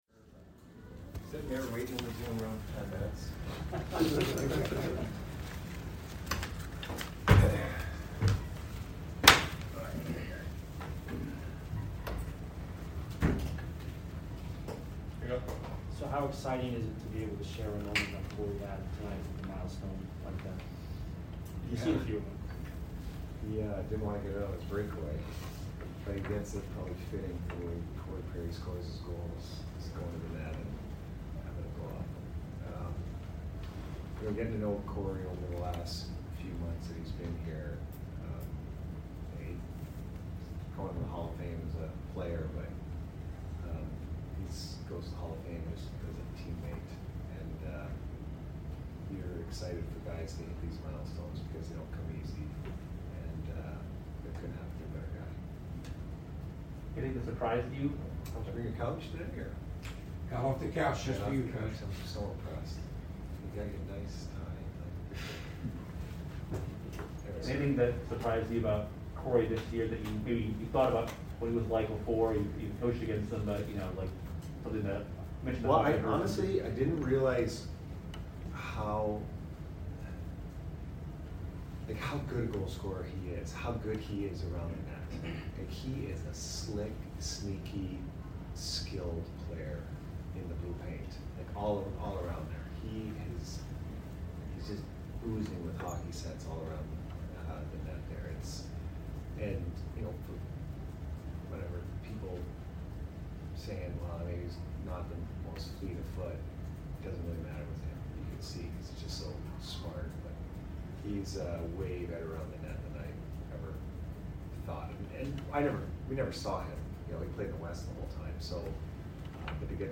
Head Coach Jon Cooper Post Game Vs EDM 2/23/2022